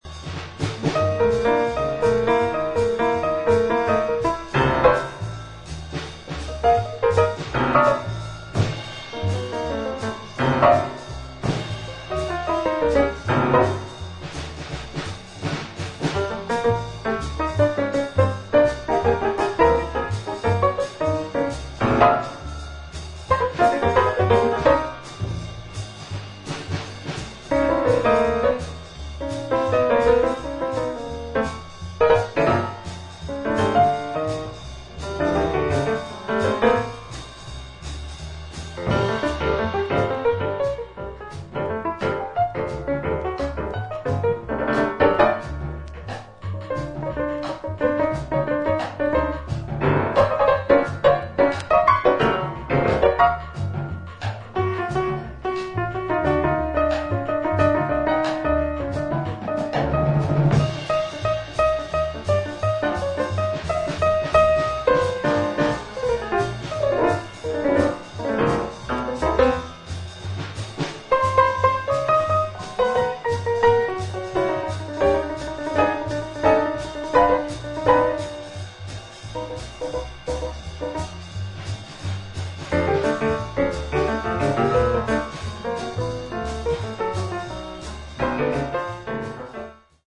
ジャズピアニスト
ベース
ドラム
ソプラノ・サクソフォーン
フリージャズ黎明期における、エネルギッシュな演奏が堪能できます。